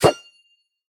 Minecraft Version Minecraft Version snapshot Latest Release | Latest Snapshot snapshot / assets / minecraft / sounds / item / trident / throw1.ogg Compare With Compare With Latest Release | Latest Snapshot
throw1.ogg